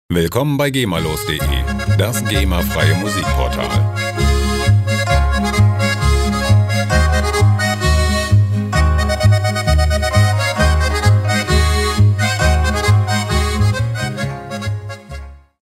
rechtefreie Folk Loops
Tempo: 131 bpm